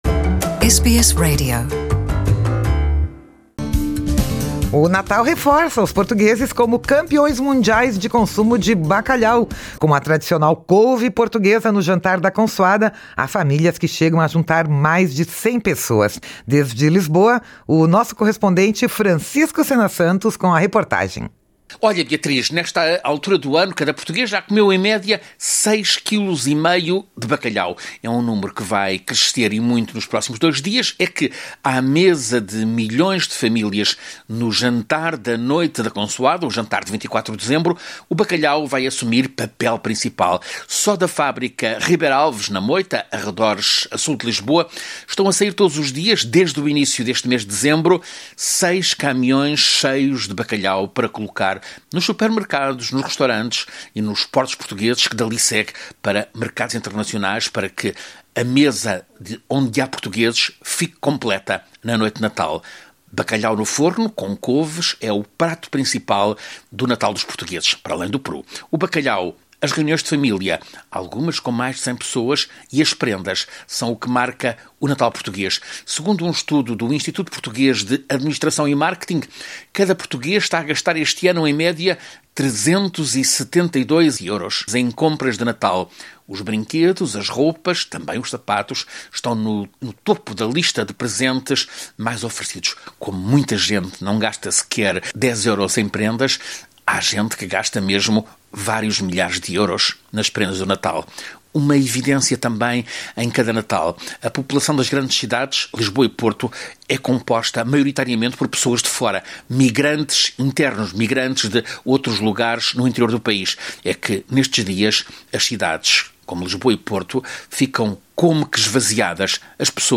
Há famílias que chegam a juntar mais de 100 pessoas. De Lisboa
reportagem